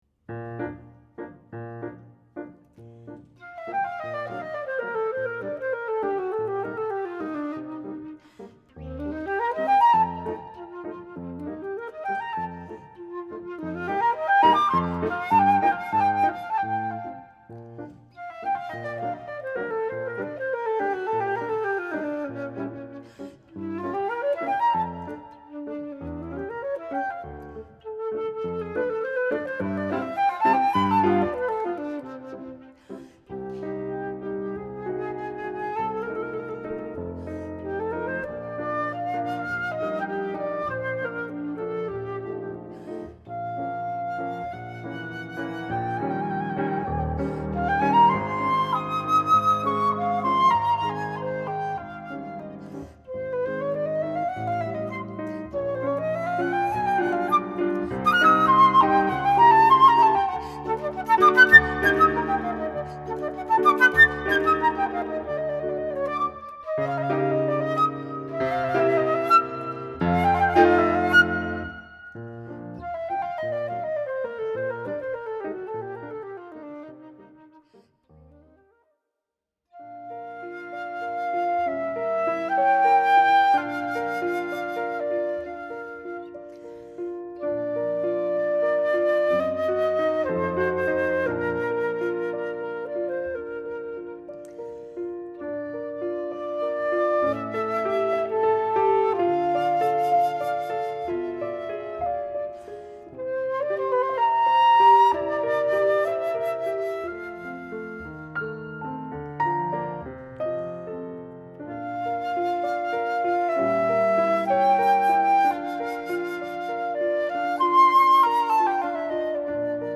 Op verschillende locaties verzorgde ik samen met een fluitiste als Duo Zeffiroso prettig ontvangen, contrastrijke programma’s: enerzijds combinaties van zowel bekende, geliefde muziek met minder bekende werken, anderzijds met afwisseling van fluit-pianoklanken en piano solo spel.